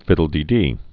(fĭdl-dē-dē)